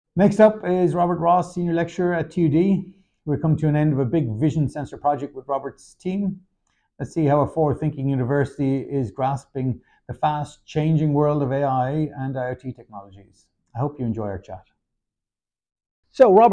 Webcast Topics: